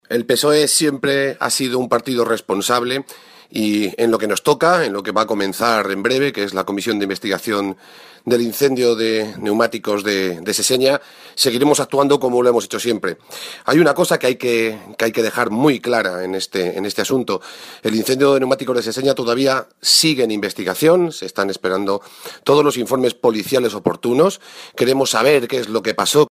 El presidente del Grupo Parlamentario Socialista en las Cortes de Castilla-La Mancha, Rafael Esteban, ha reiterado el compromiso de su grupo para que se pueda conocer "en profundidad" todo lo acontecido en el cementerio de neumáticos de Seseña.
Cortes de audio de la rueda de prensa